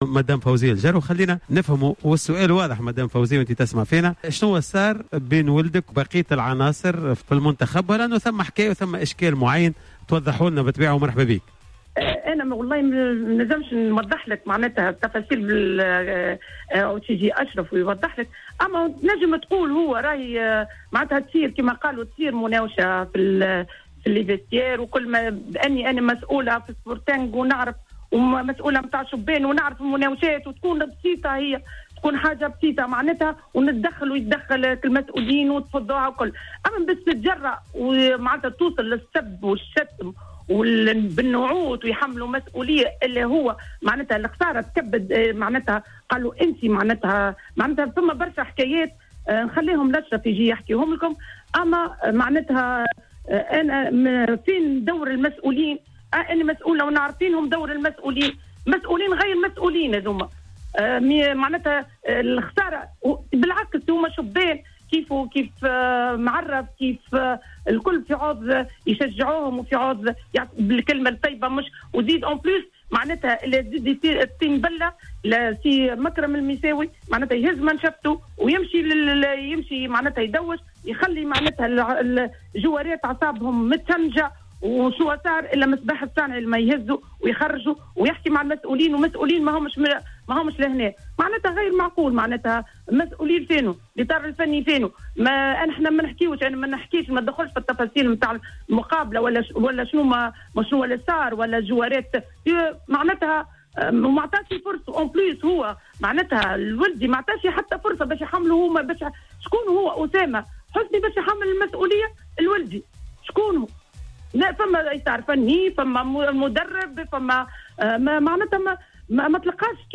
تدخلت خلال حصة Planète Sport ، التي تم تخصيصها لتقييم مشاركة المنتخب الوطني في...